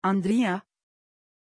Pronuncia di Andreia
Turco
pronunciation-andreia-tr.mp3